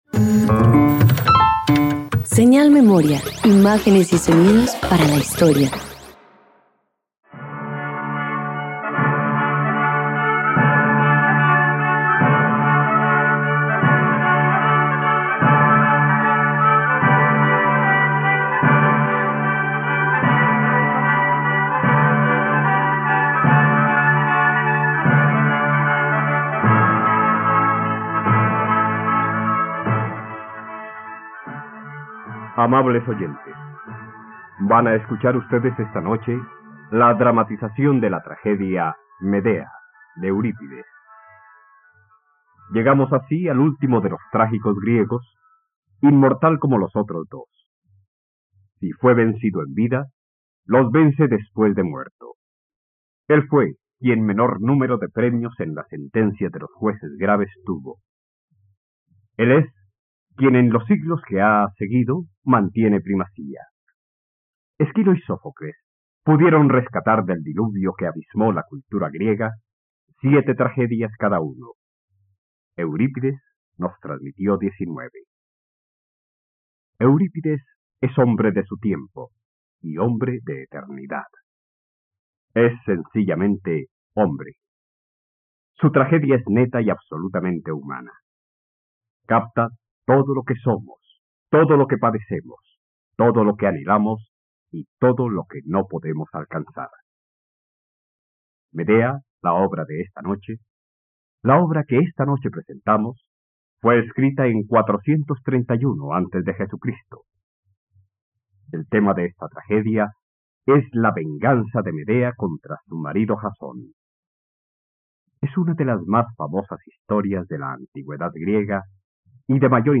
Medea - Radioteatro dominical | RTVCPlay
..Radioteatro. Escucha la adaptación del mito de Medea, del poeta trágico griego Eurípides, en la plataforma de streaming de todos los colombianos RTVCPlay.